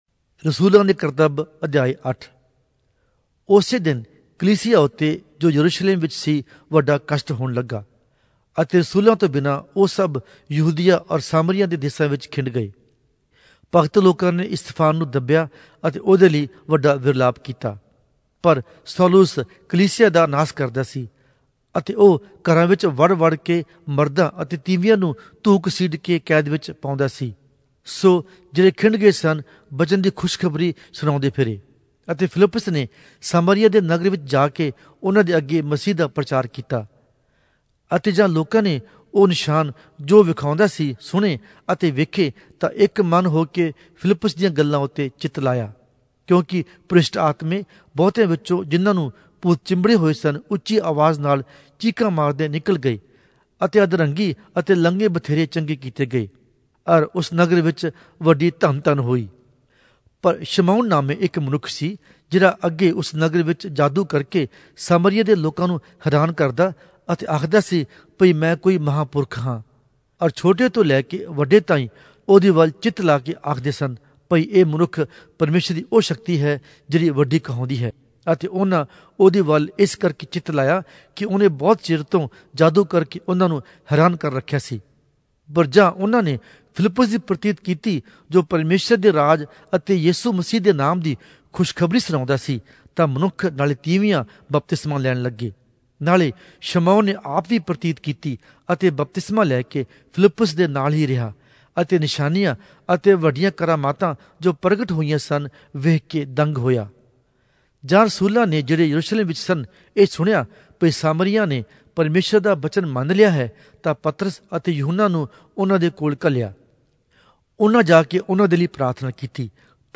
Punjabi Audio Bible - Acts 9 in Net bible version